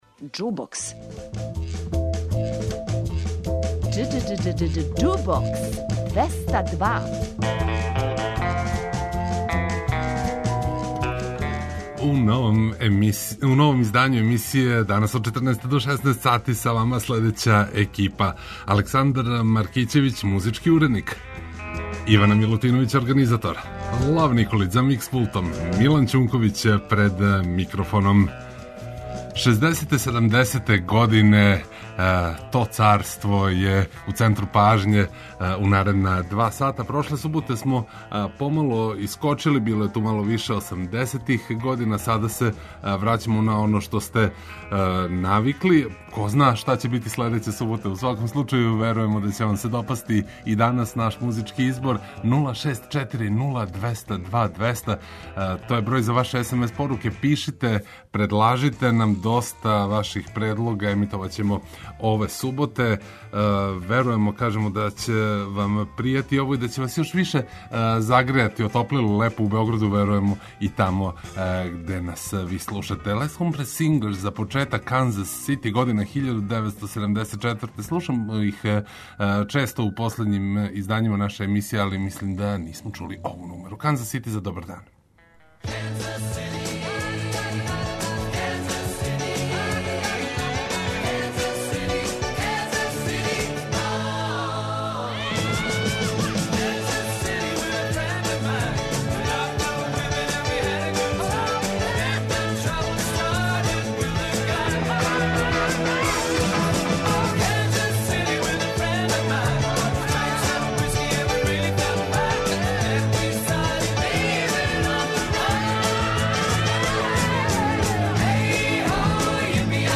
Прошле суботе смо вас изненадили са неколико песама из осамдесетих, чак и једном из 1990, а сад се враћамо на уобичајен колосек, па ће репертоар емисије поново чинити одабрани класици из шездесетих и седамдесетих година.